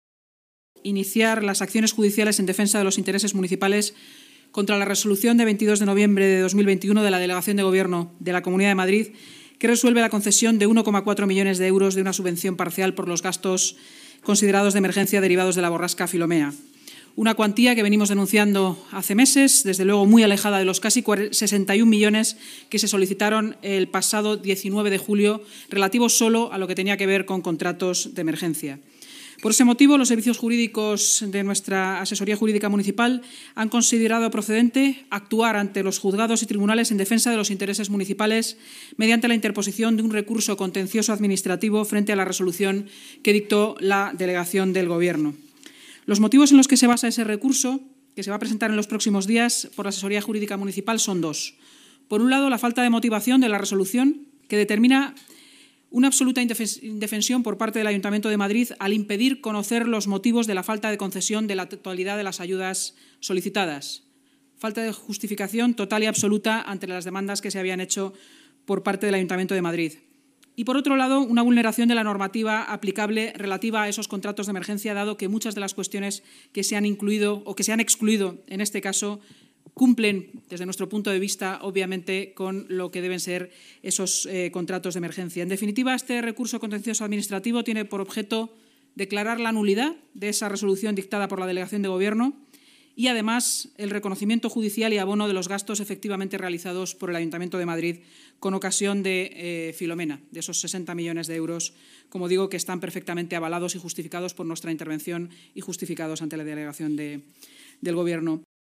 Así lo ha anunciado la portavoz municipal, Inmaculada Sanz, en rueda de prensa.